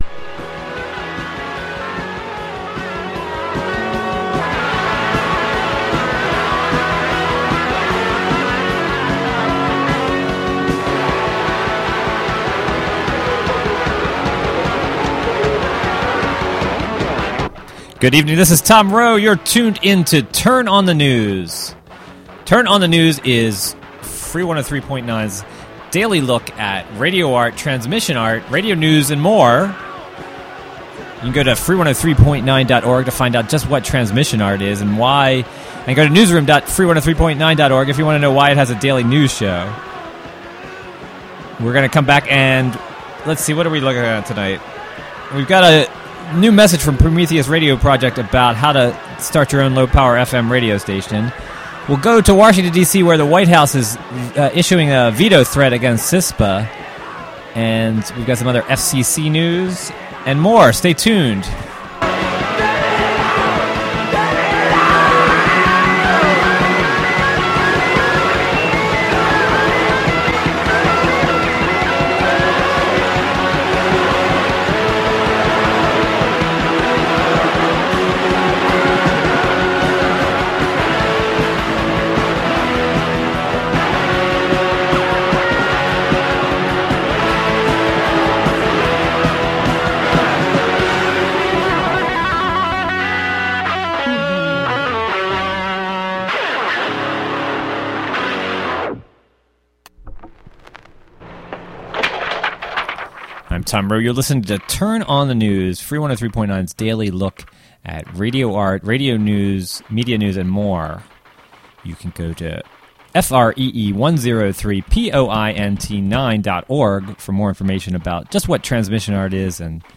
Turn On the News is a daily radio news program fea...